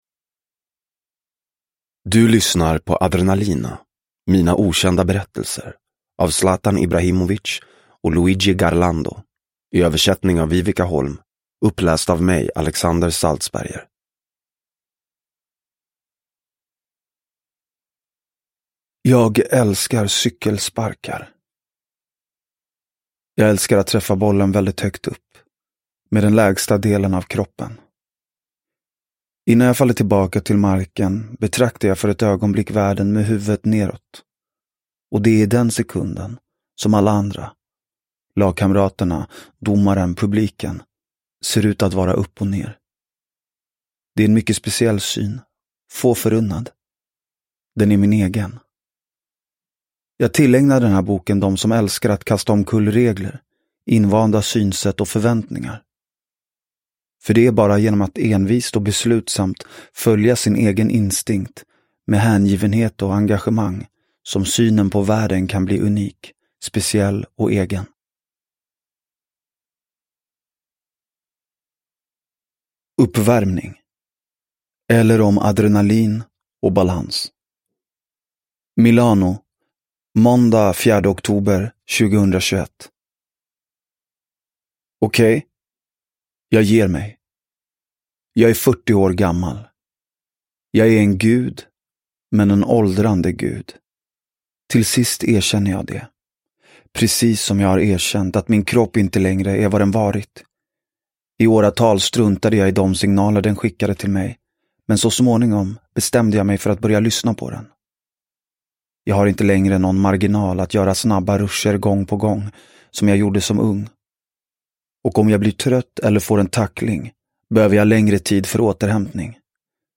Adrenalina : mina okända berättelser – Ljudbok – Laddas ner